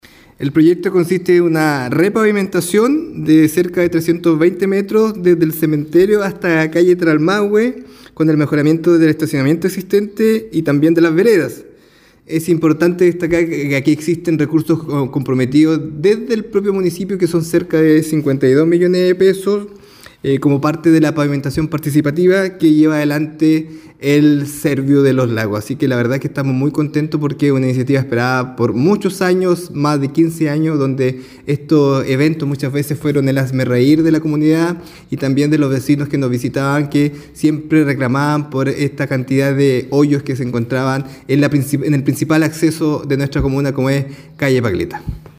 El Alcalde Carrillo señalo que : » Además, es un esfuerzo conjunto donde el municipio ha comprometido más de 50 millones de pesos a través del programa de pavimentación participativa».